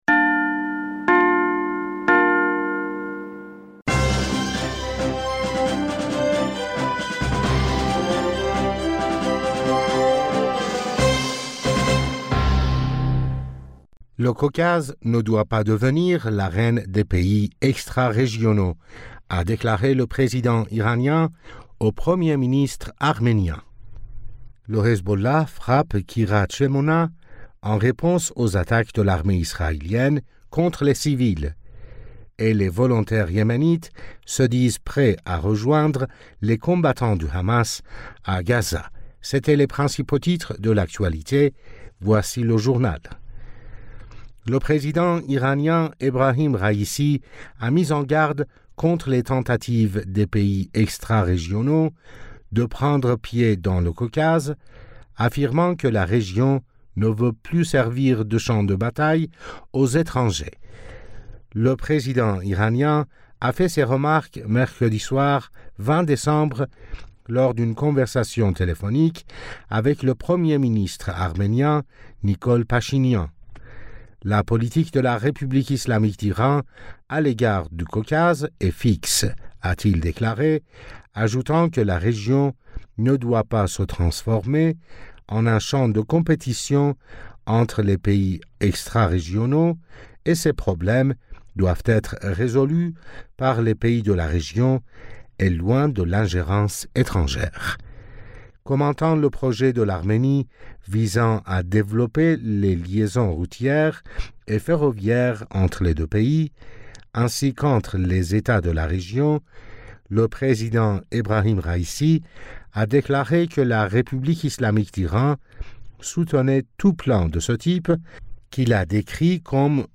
Bulletin d'information du 21 Decembre 2023